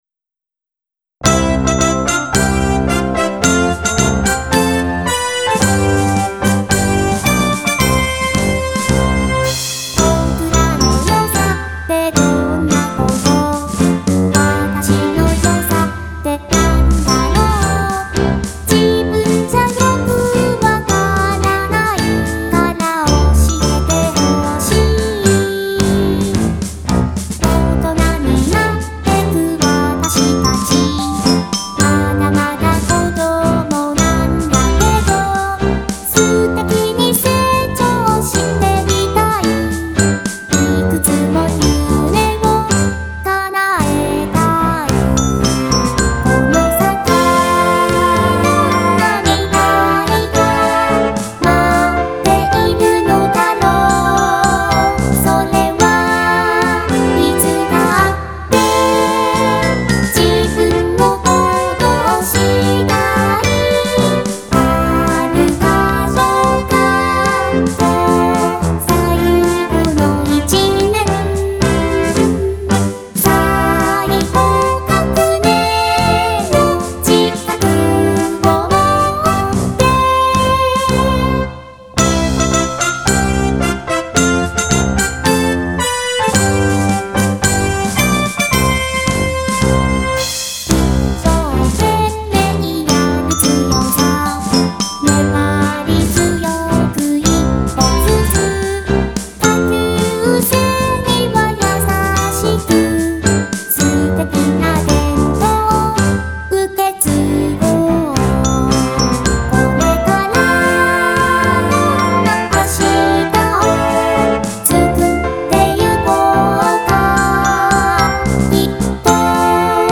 ・2023「崖の上のアリドル」職員サプライズ演奏＆パフォーマンスDEMO